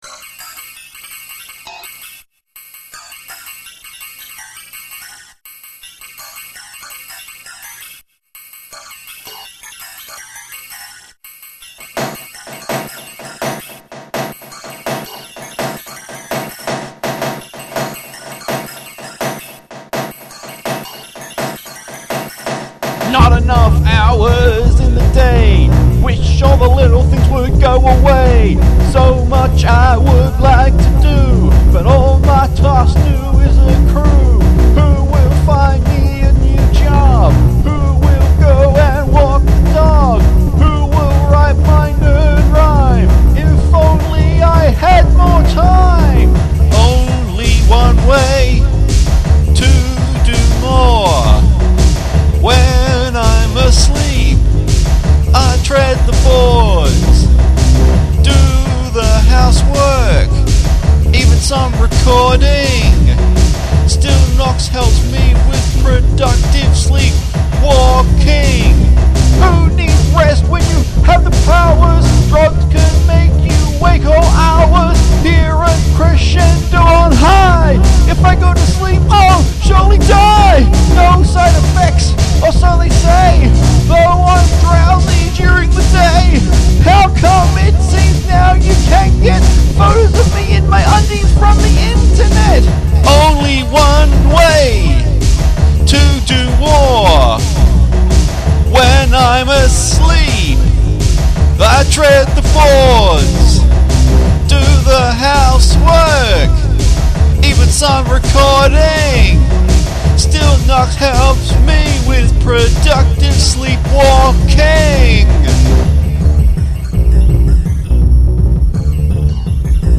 Crescendo